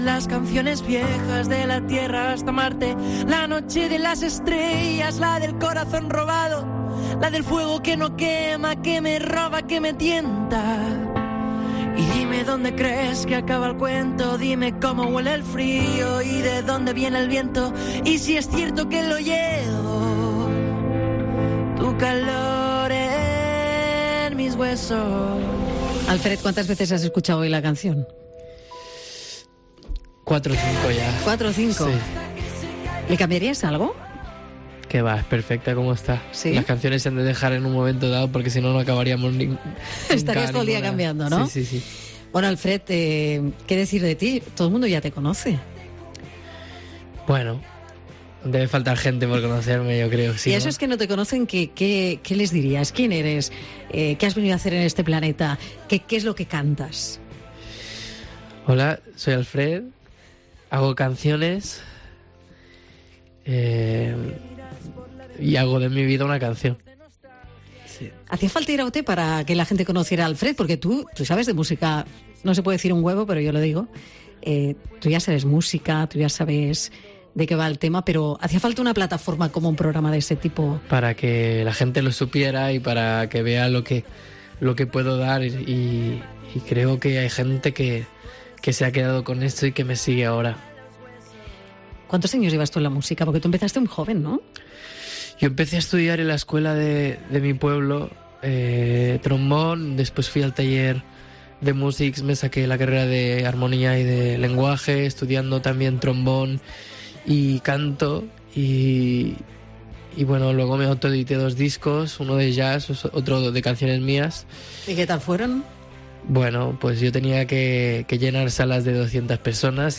Entrevista en COPE a Alfred García